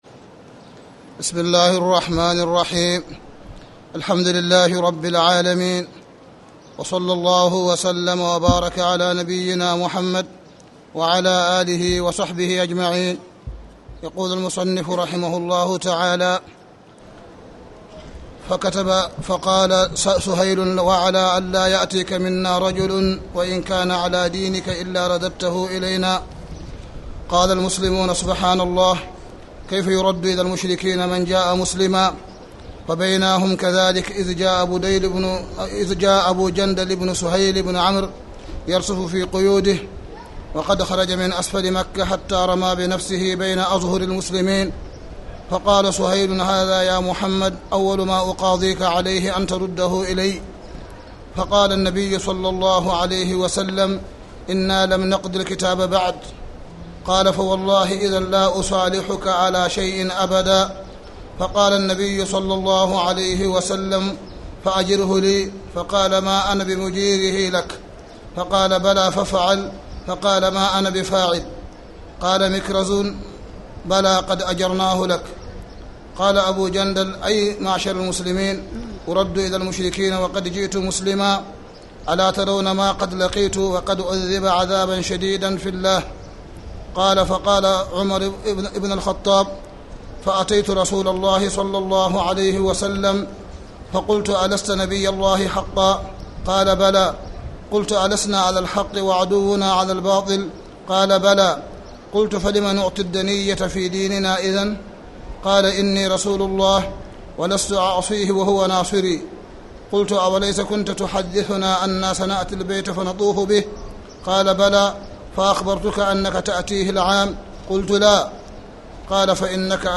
تاريخ النشر ٢٦ شعبان ١٤٣٨ هـ المكان: المسجد الحرام الشيخ: معالي الشيخ أ.د. صالح بن عبدالله بن حميد معالي الشيخ أ.د. صالح بن عبدالله بن حميد باب ما يجوز من الشروط مع الكفار